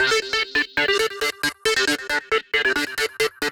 Index of /musicradar/future-rave-samples/136bpm
FR_RaveSquirrel_136-D.wav